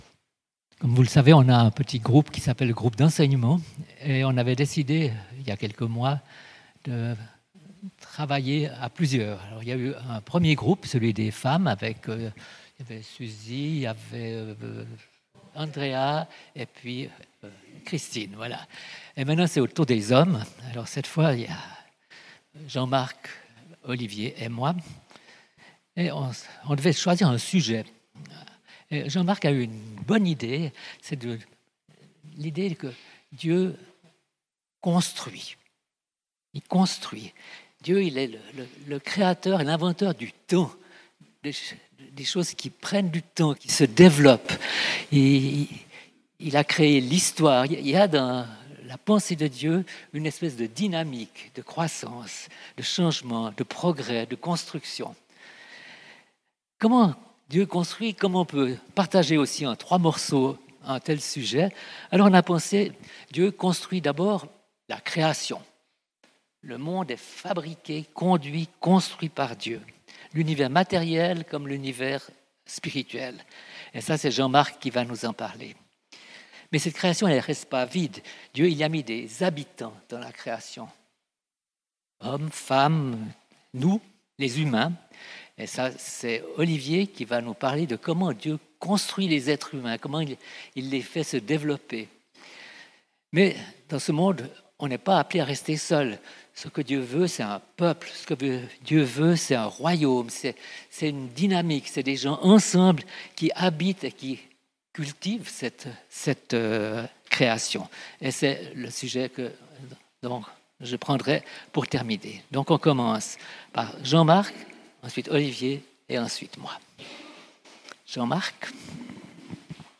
Type De Rencontre: Culte